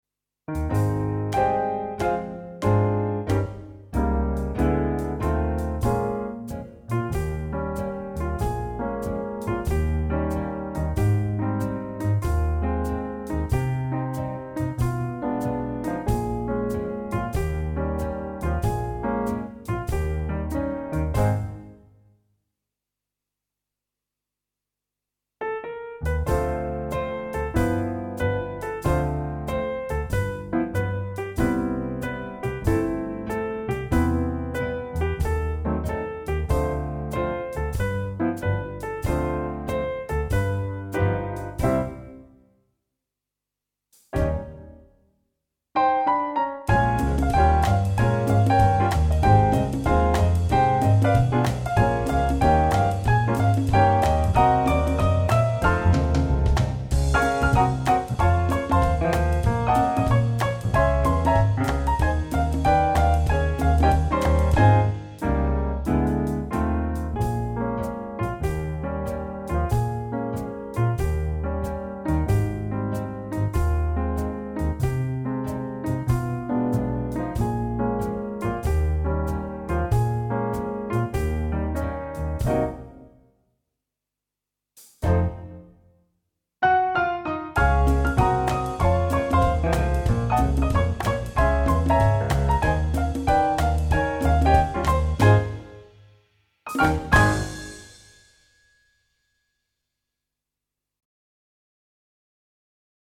Tempo Change